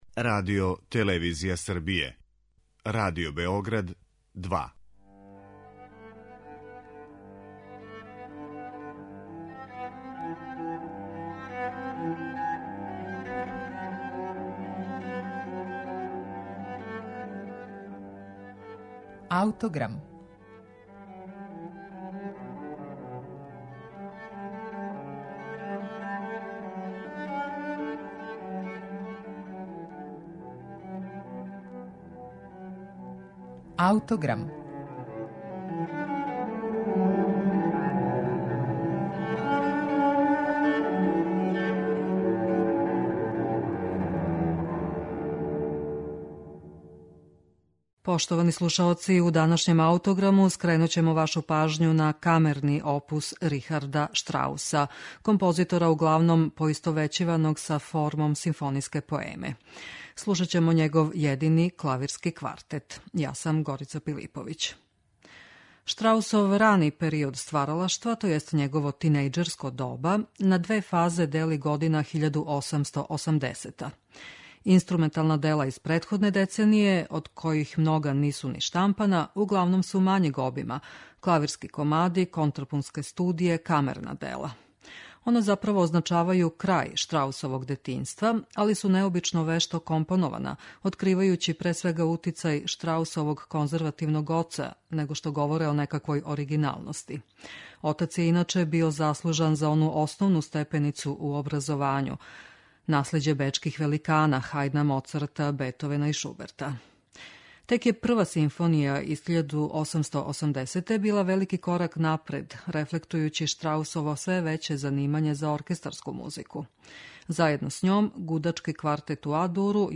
Клавирски квартет Рихарда Штрауса